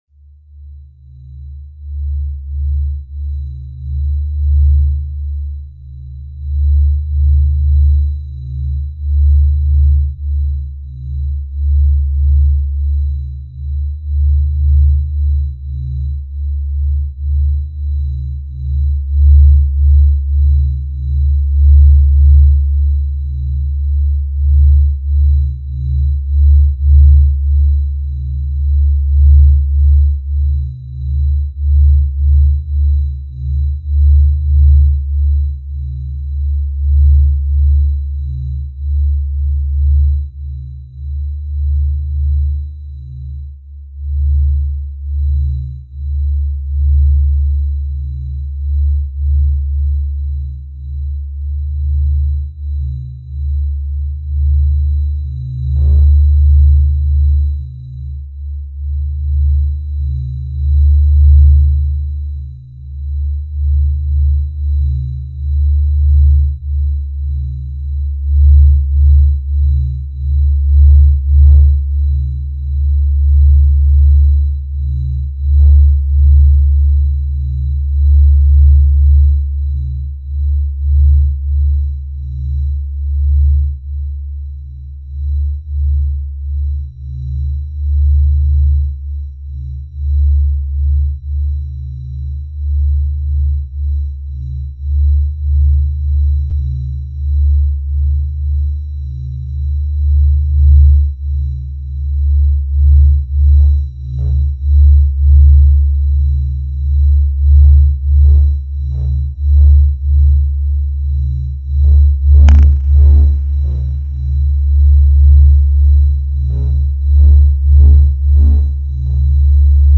На этой странице собраны редкие звуки Солнца, преобразованные из электромагнитных колебаний в доступные для прослушивания аудиофайлы.
Звук солнечных вспышек в космической пустоте